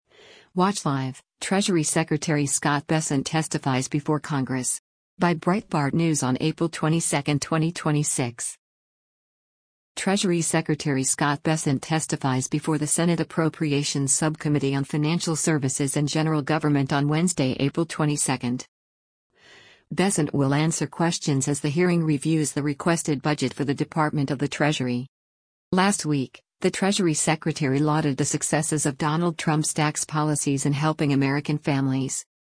Treasury Secretary Scott Bessent testifies before the Senate Appropriations Subcommittee on Financial Services and General Government on Wednesday, April 22.